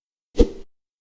Melee Weapon Air Swing 1.wav